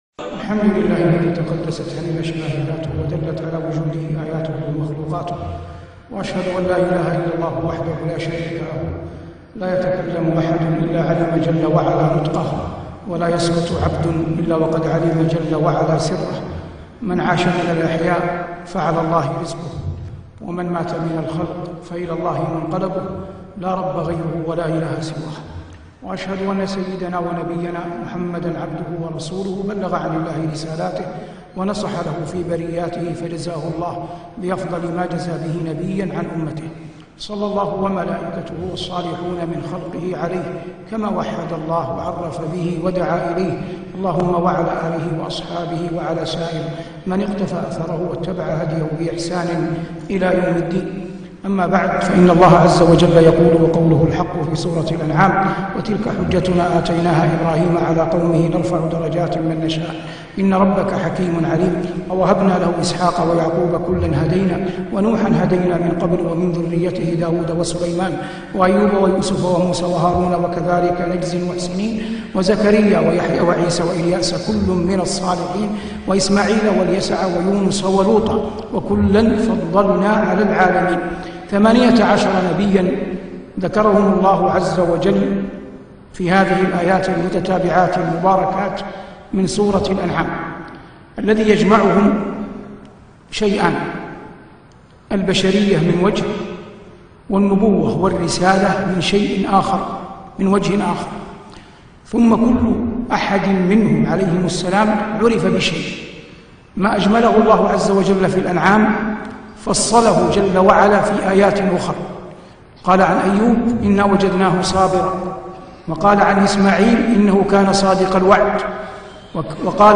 محاضرة بعنوان "كيف نكون قدوة؟" بجامعة الملك عبد العزيز بجدة 20ـ8ـ1438